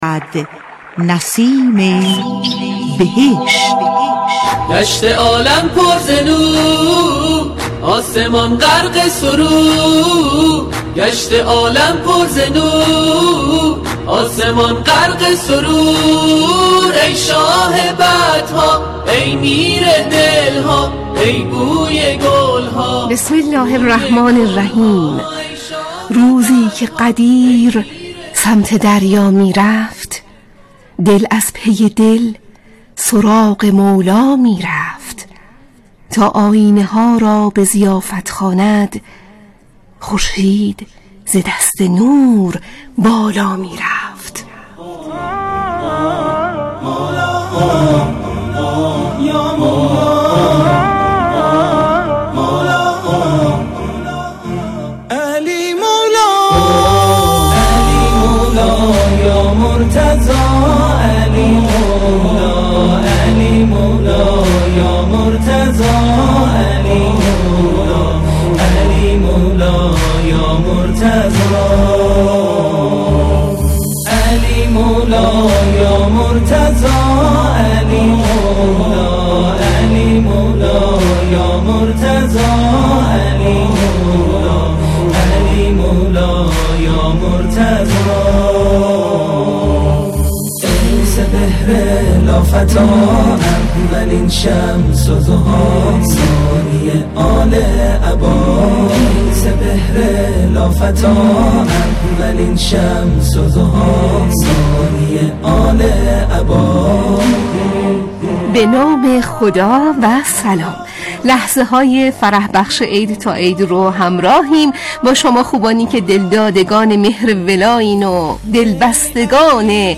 مجله صبحگاهی «نسیم بهشت» در ایام دهه ولایت و امامت با موضوع تبیین غدیر و خطبه‌خوانی غدیر صبح‌ها به روی آنتن رادیو قرآن می‌رود.
از دیگر بخش‌های این مراسم بخش نسیم ولایت با محوریت امامت در قرآن و نهج‌البلاغه، خوانش معنای خطبه غدیر و پویش خطبه‌خوانی با لهجه‌ها و گویش‌های مختلف، گزارش مردمی و ارتباط با مكه و مدینه و نجف اشرف و شعرخوانی است.